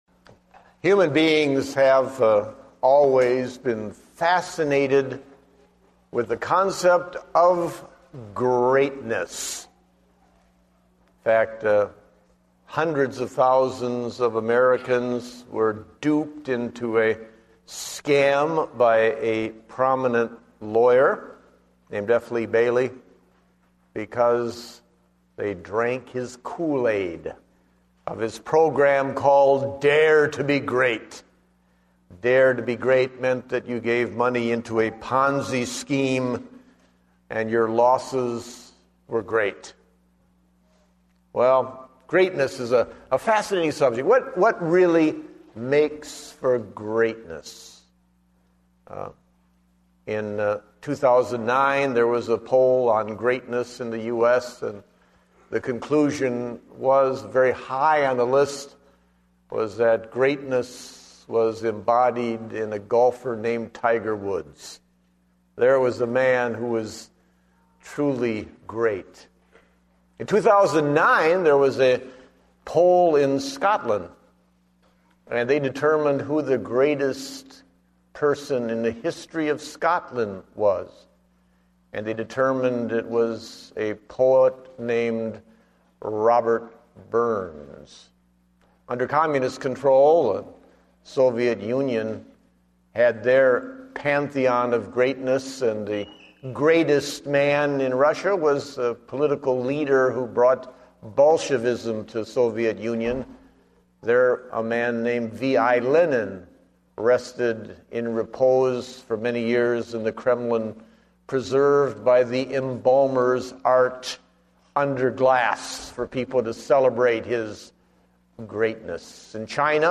Date: January 31, 2010 (Morning Service)